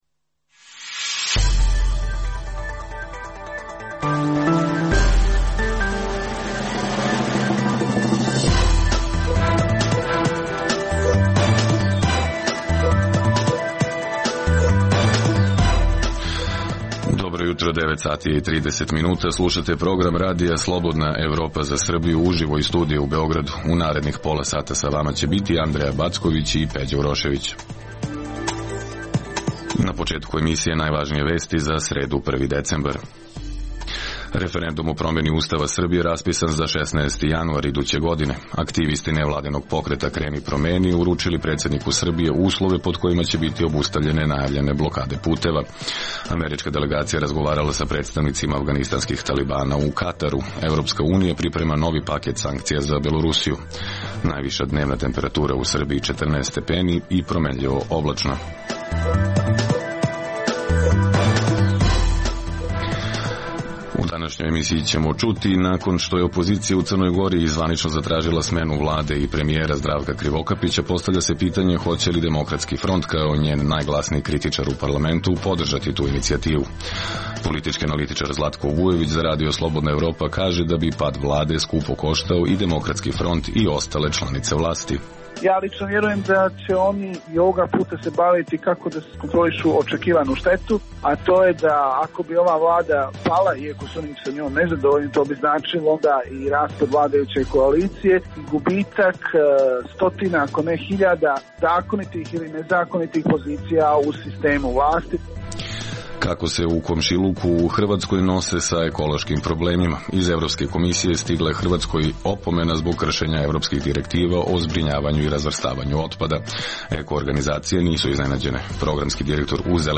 Emisija namenjena slušaocima u Srbiji koja sadrži lokalne, regionalne i vesti iz sveta te tematske priloge o aktuelnim dešavanjima priče iz svakodnevnog života.